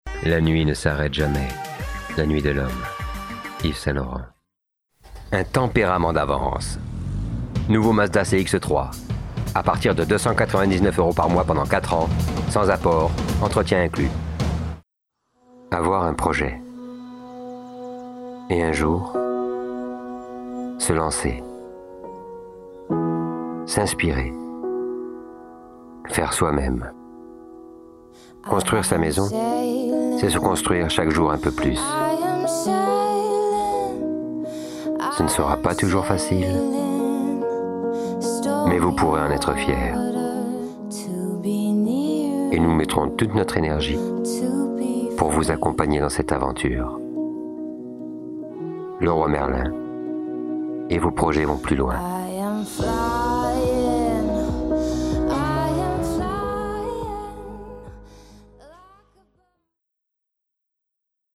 Démo voix Pub